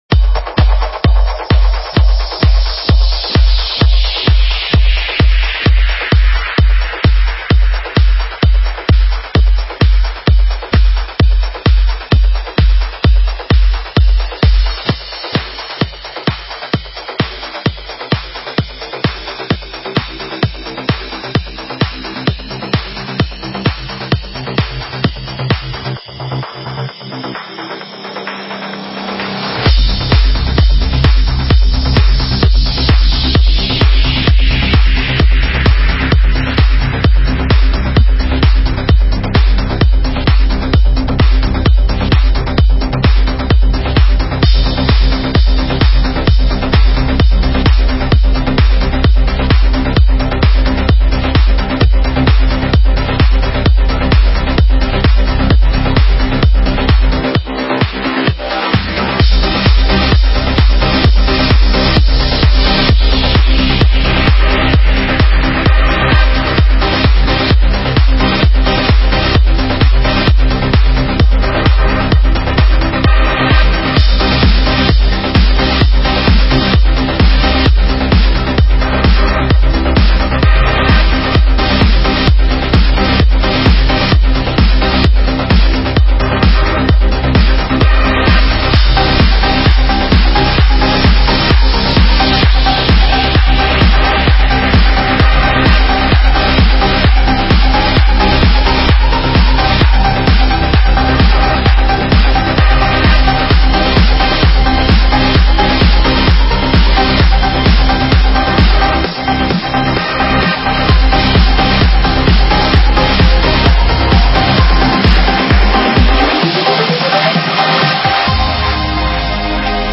Стиль: ProgressiveTrance